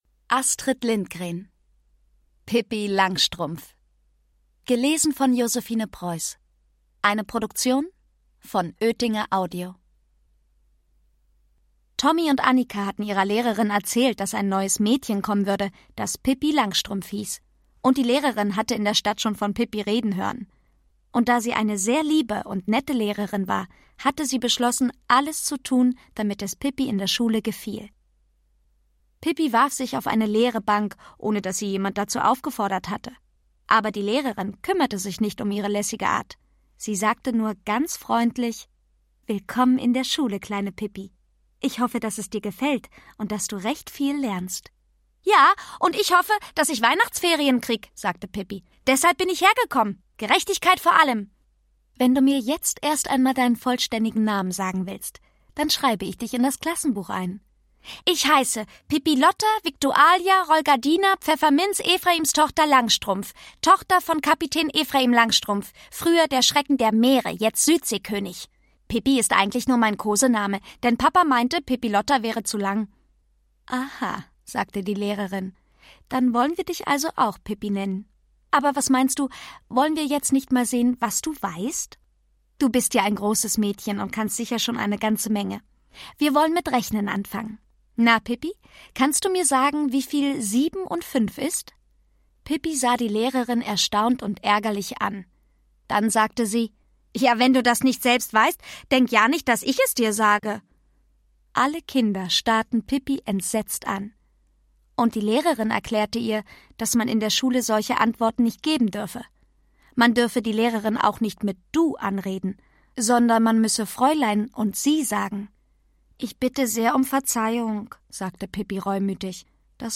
Alles von Pippi Langstrumpf Astrid Lindgren (Autor) Josefine Preuß (Sprecher) Audio-CD 2020 | 4.